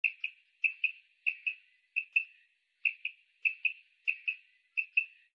Звуки перепёлки
Самка перепелки зовет самца